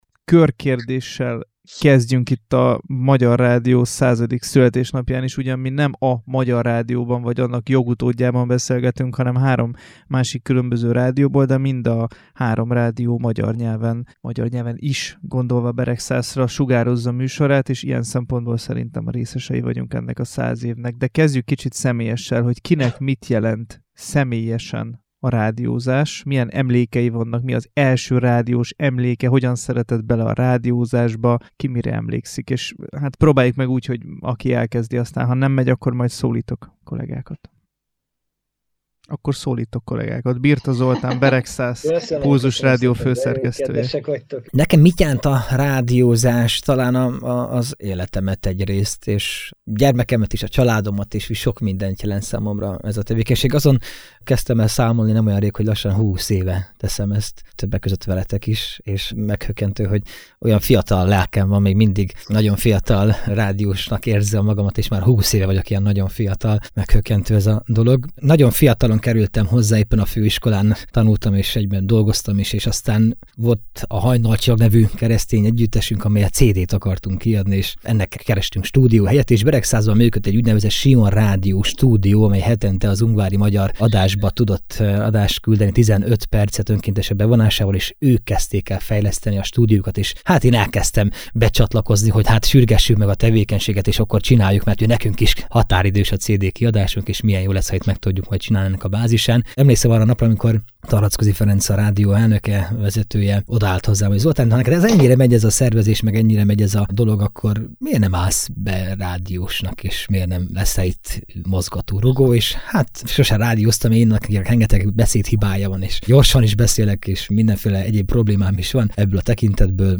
Száz évvel ezelőtt indult el a Magyar Rádió, vagyis a magyar nyelvű rádiózás is. Ennek apropóján ma három református rádió főszerkesztőjével beszélgetünk arról, mit jelenet a rádiózás 2025-ben, hogyan jelenik meg a műsorainkban az, hogy református szellemiségben dolgozunk, és persze eljátszunk a gondolattal: lesz-e még aki egy rádióműsorban beszél majd a magyar rádiózás születésének kétszázadik évfordulóján, 2125-ben…